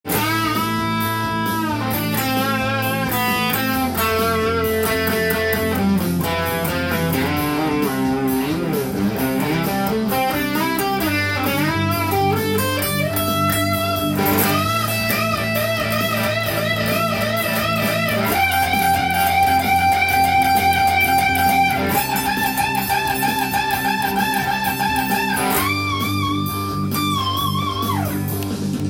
ポジション移動し　カンタンにギターソロを盛り上げることができます。
ロック風ギターソロ